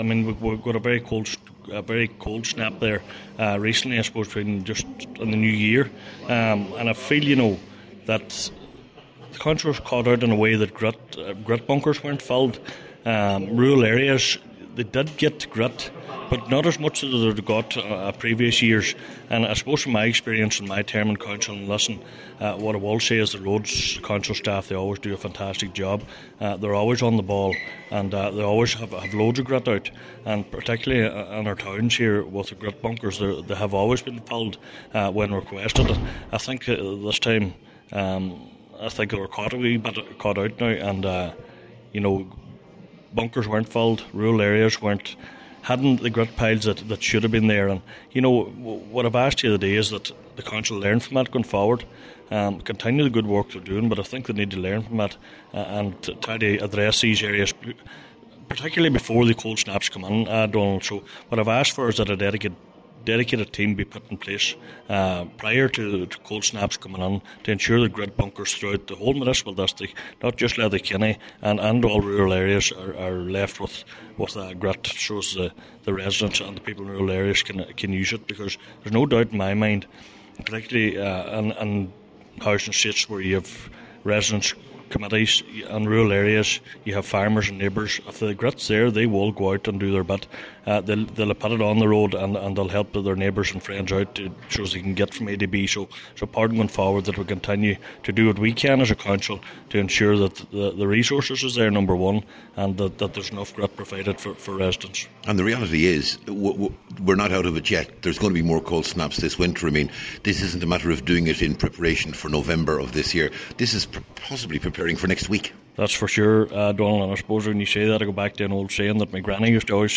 That’s one of the issues that came to light at a meeting of Letterkenny Milford Municipal District this afternoon.
Cllr Donal Mandy Kelly told the meeting he and other members acknowledge the challenge, but said this is the first time he has seen the council caught out: